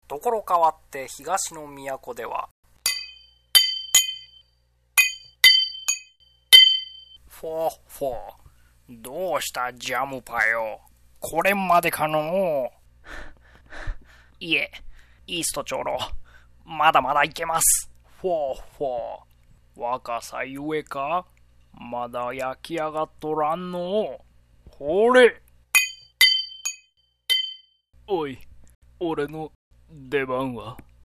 ホントは「もう少しいい声なんだよ」って一応言わせてください。